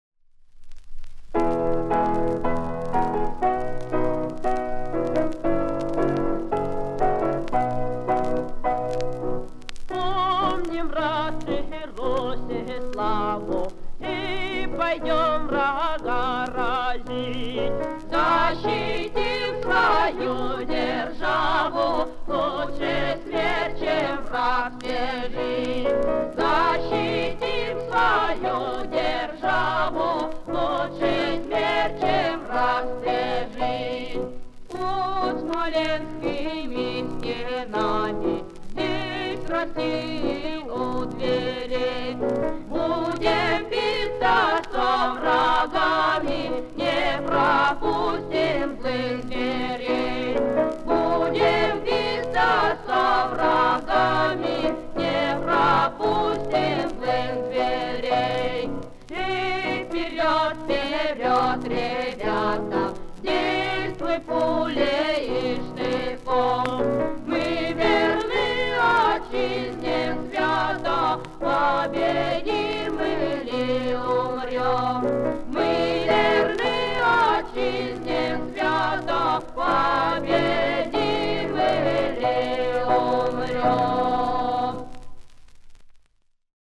Солдатская песня 1812 года